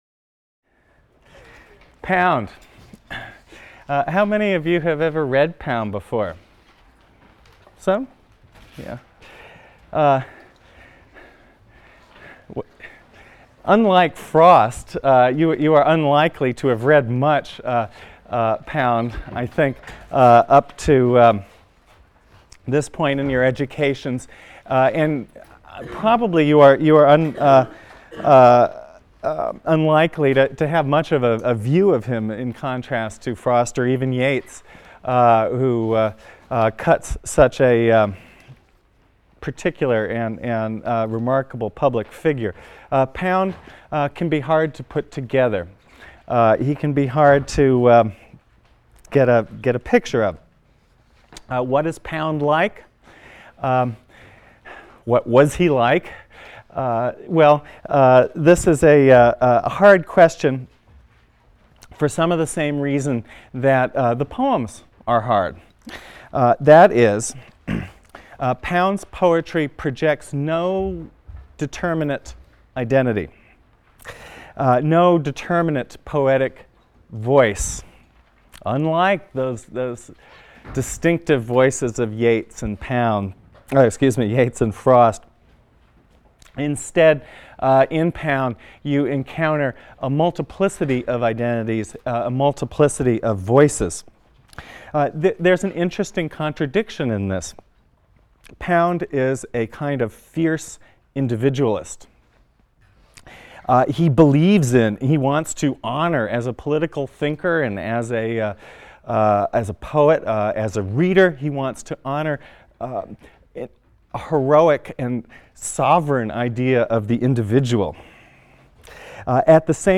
ENGL 310 - Lecture 9 - Ezra Pound | Open Yale Courses